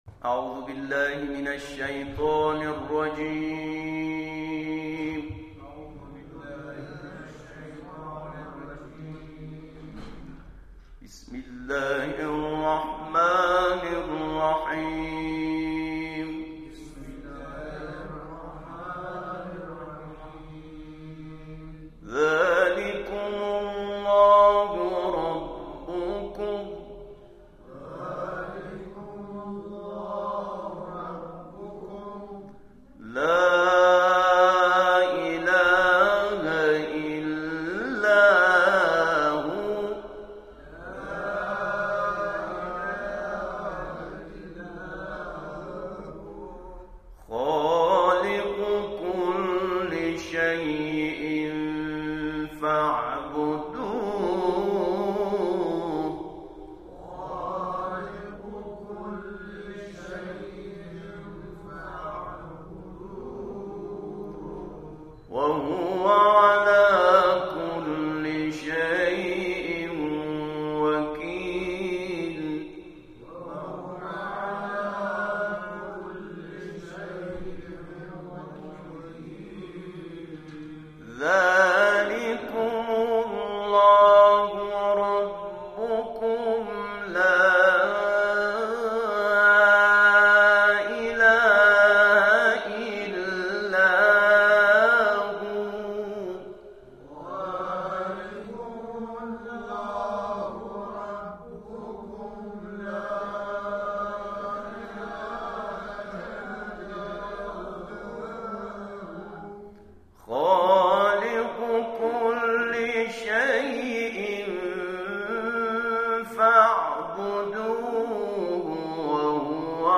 با حضور قاریان از سنین مختلف در مسجد قبا
به شیوه ترتیل و تحقیق تلاوت کردند
در پایان گزارش تصویری و تلاوت جمع‌خوانی در پایان جلسه ارائه می‌شود.